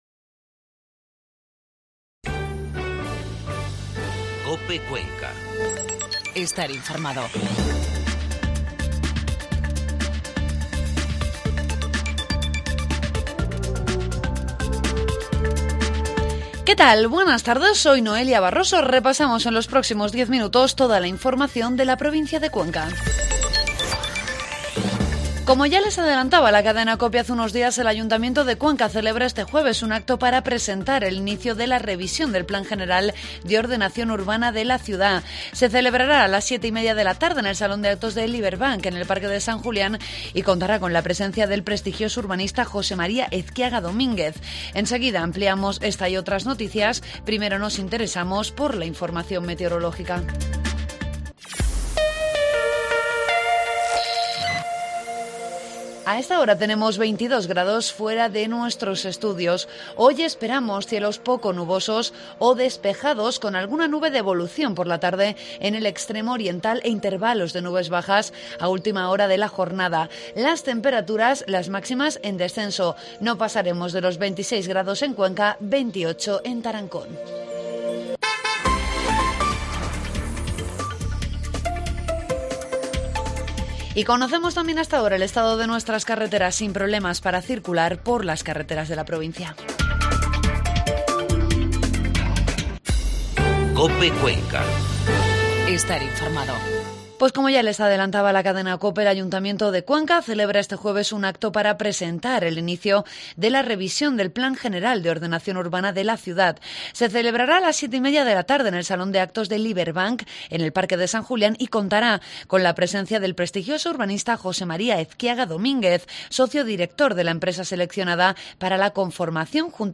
Informativo mediodía COPE Cuenca 26 de septiembre